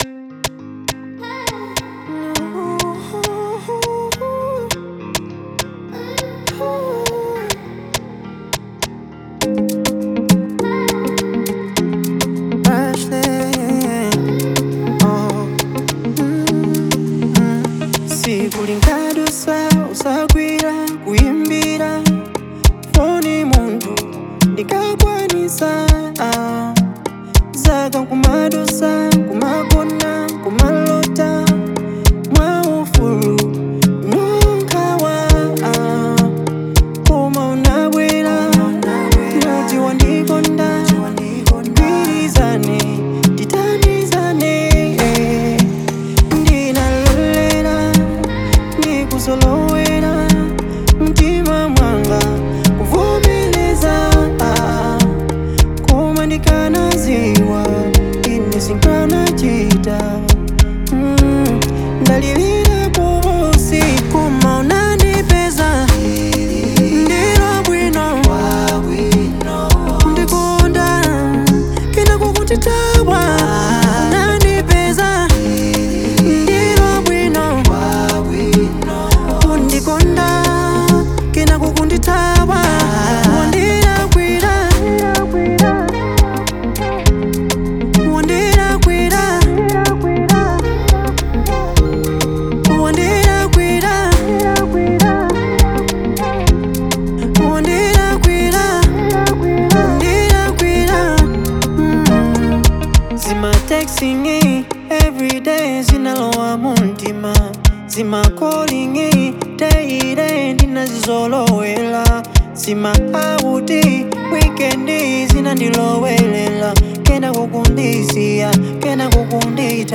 Afro-Beat